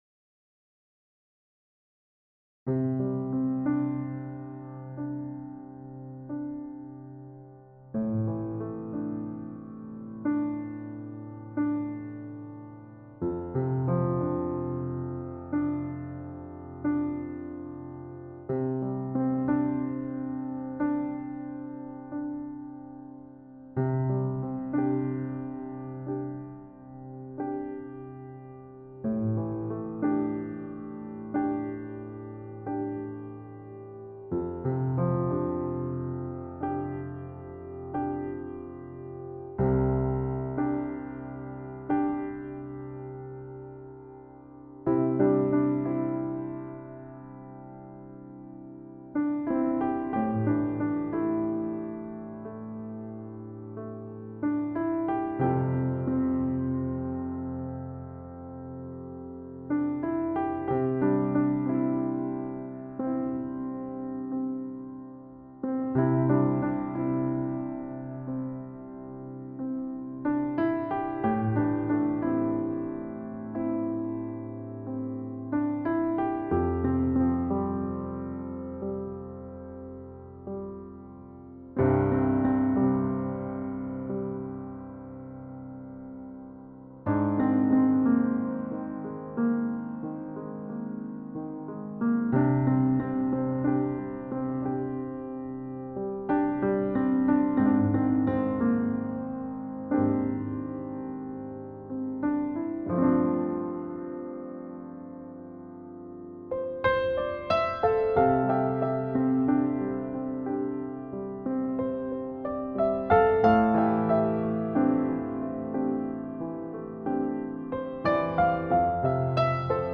Largo [40-50] plaisir - orchestre symphonique - - -